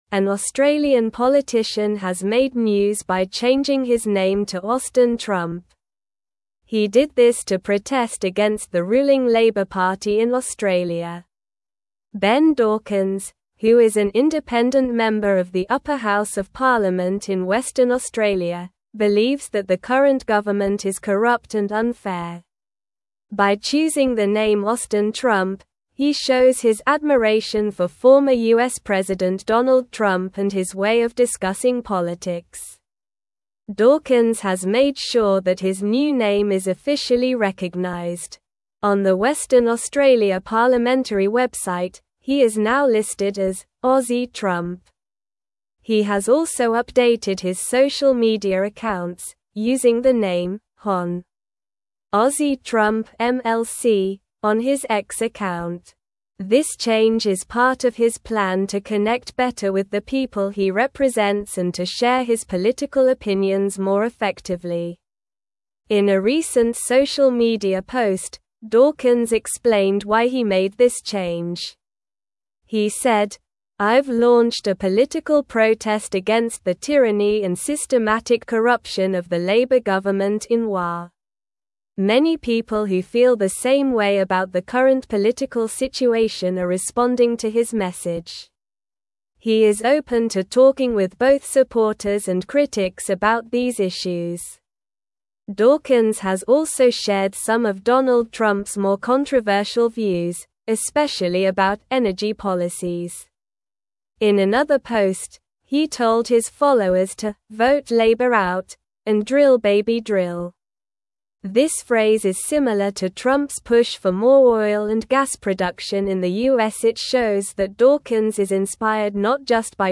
Slow
English-Newsroom-Upper-Intermediate-SLOW-Reading-Australian-Politician-Changes-Name-to-Austin-Trump.mp3